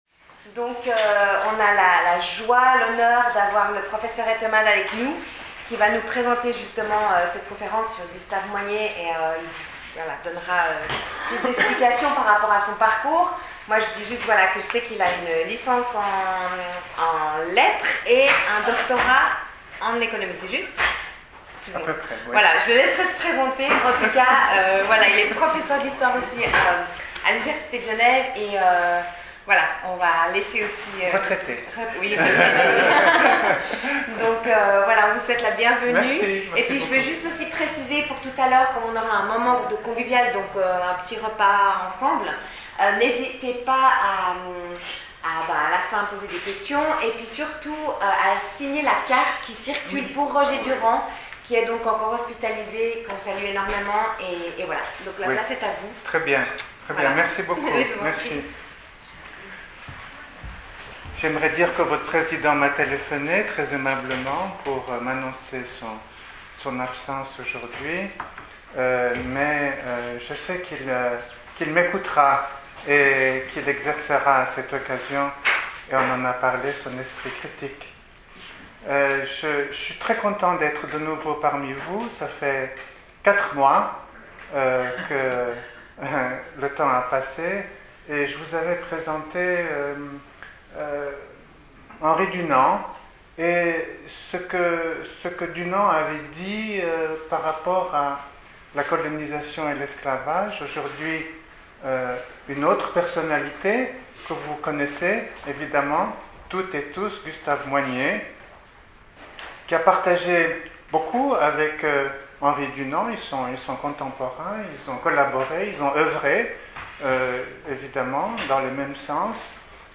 PODCAST Enregistrement réalisé le samedi 3 juin 2023 au Centre Henry Dunant Durée de l'enregistrement